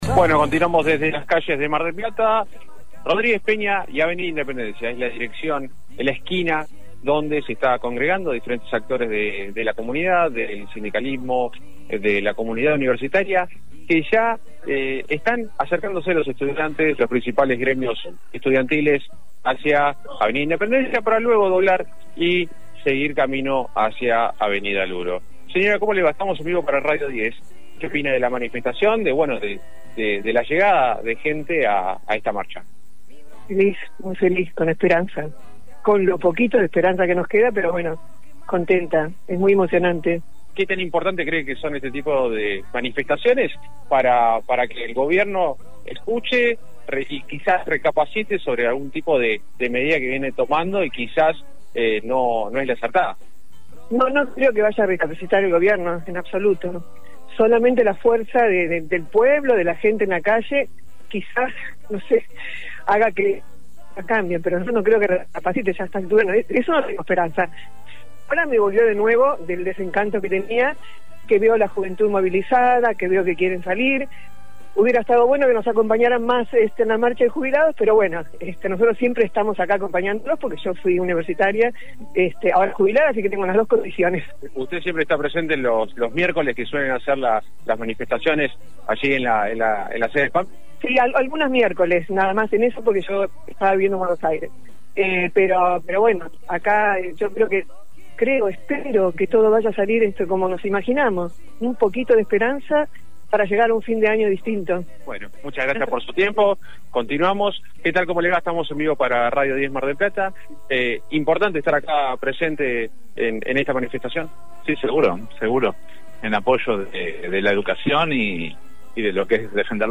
Radio 10 Mar del Plata transmitió en vivo la movilización que recorrió el centro de la ciudad con sindicatos, estudiantes, jubilados y organizaciones sociales.